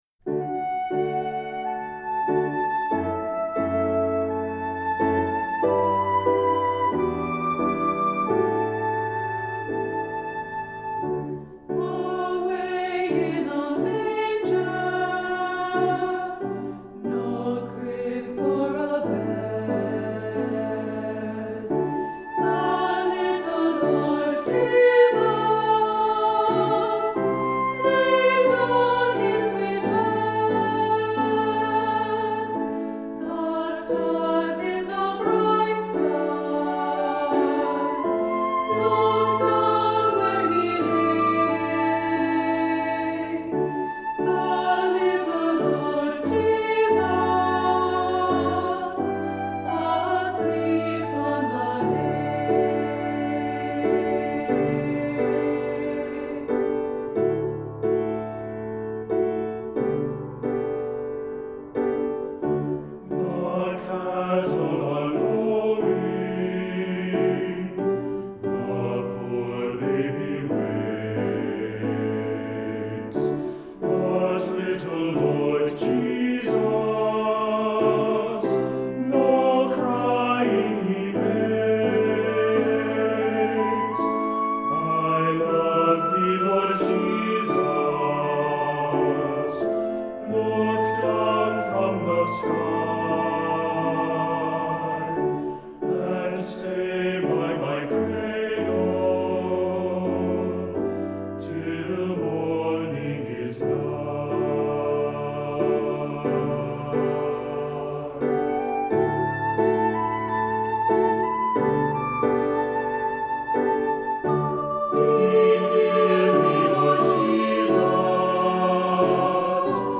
Accompaniment:      Keyboard, Flute
Music Category:      Choral